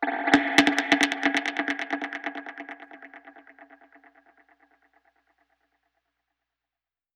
Index of /musicradar/dub-percussion-samples/134bpm
DPFX_PercHit_B_134-02.wav